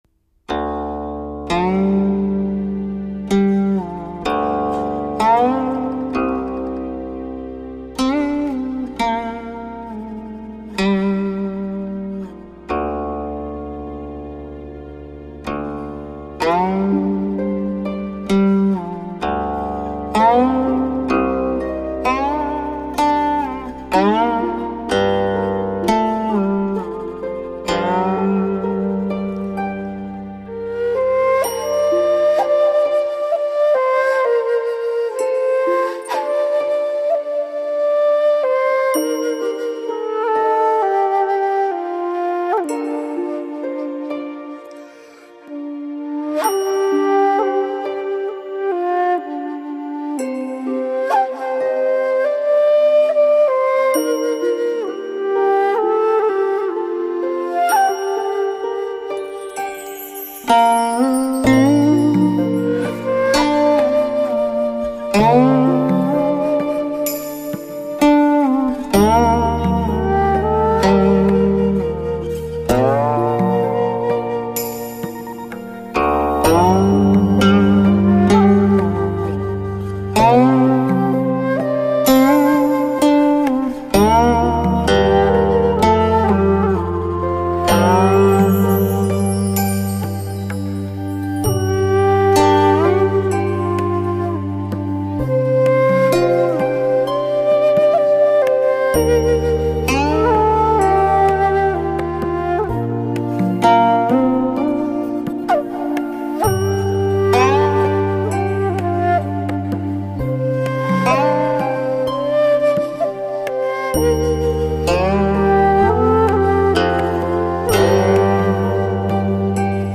古琴
笛子/箫/竖笛/Bansuri